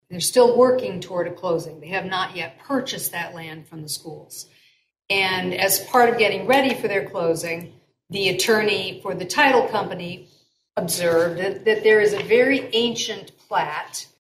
City Attorney Megan Angell said during the Council’s June 26 meeting the city is being asked to vacate, abandon and discontinue the public right-of-way in order to satisfy a title company requirement for the closing of the sale between the Coldwater Community Schools and the developer of the Coldwater Senior Villas.